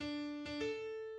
Melodie